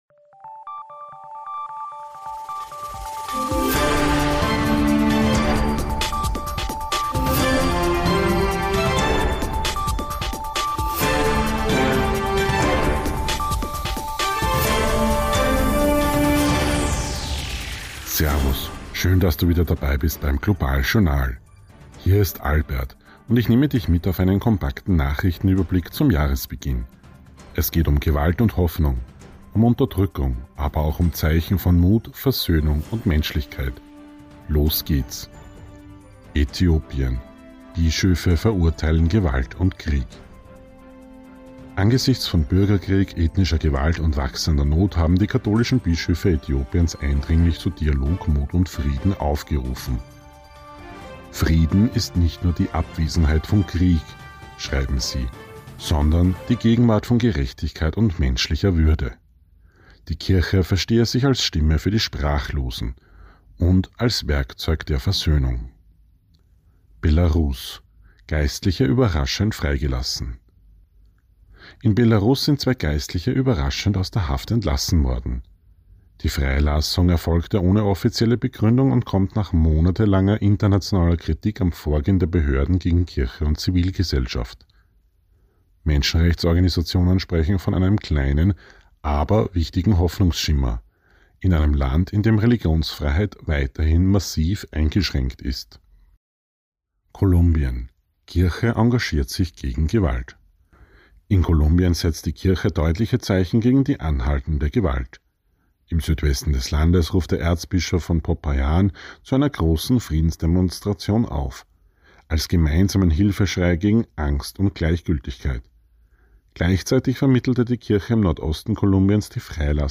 News Update Jänner 2026